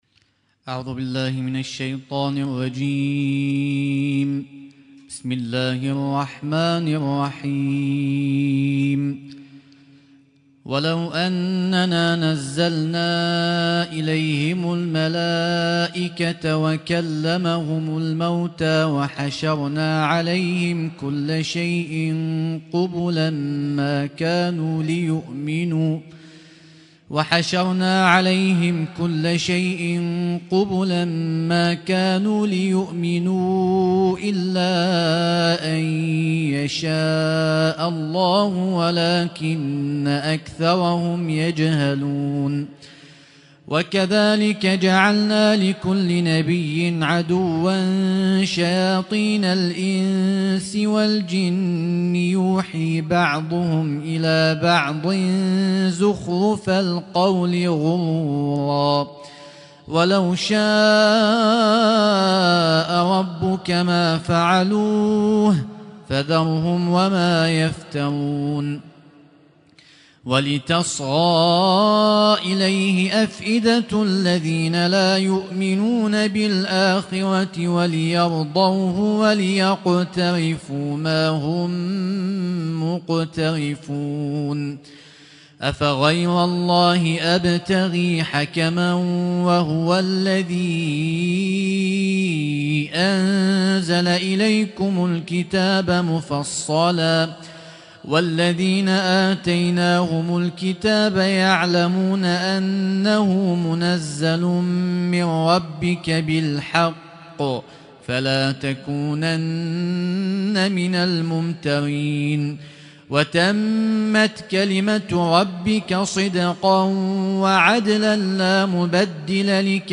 ليلة (8) من شهر رمضان المبارك 1446هـ